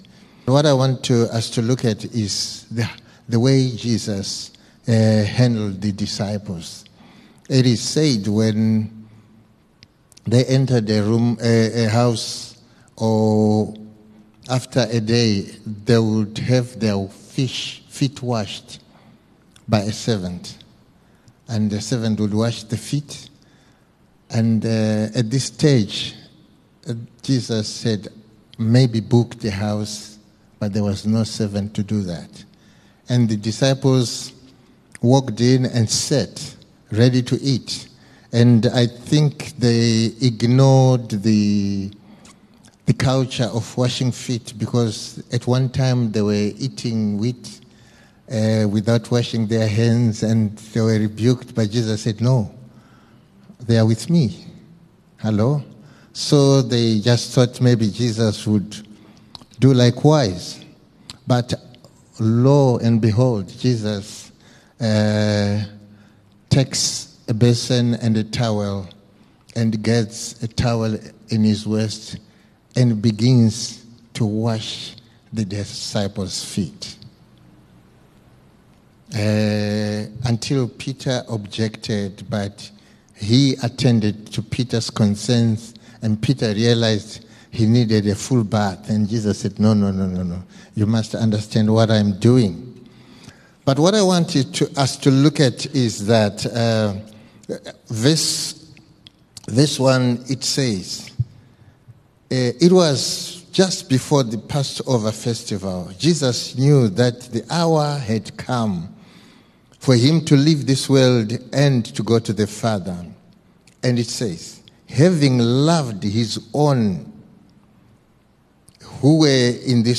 Mid week combined connect group meeting